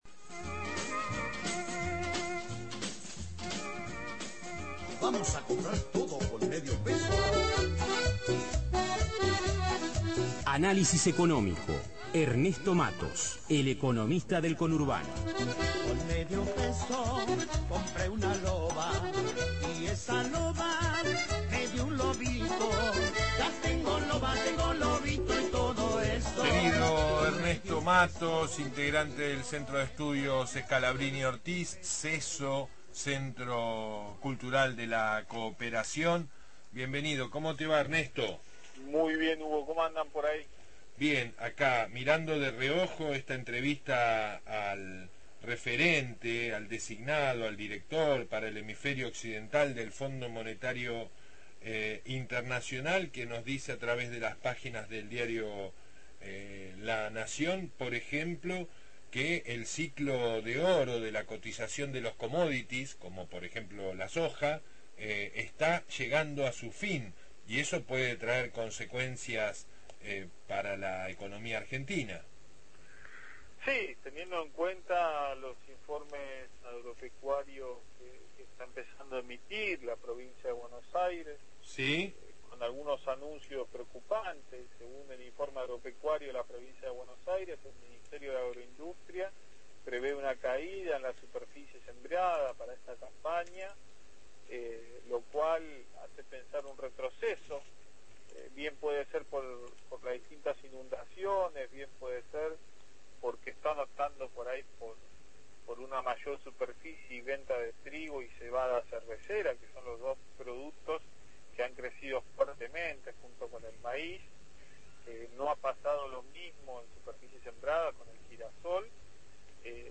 Columna de análisis económico (04/04/2017) – Radio Universidad